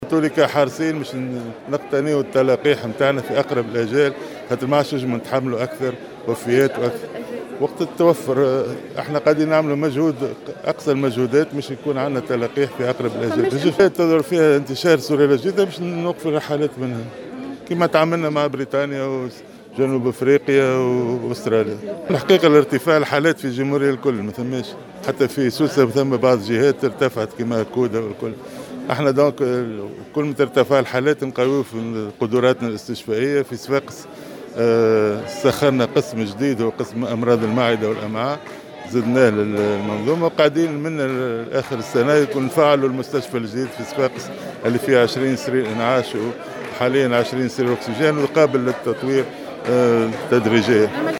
وأضاف في تصريح اليوم لـ"الجوهرة أف أم"، على هامش زيارته لولاية سوسة لافتتاح وحدة التحاليل الفيروسية الجزيئية التابعة لمخبر تحاليل مستشفى سهلول، أنه سيتم تعليق الرحلات في اتجاه الدول التي تسجّل إصابات بالسلالة الجديدة من فيروس كورونا على غرار بريطانيا وجنوب افريقيا و استراليا والدنمارك.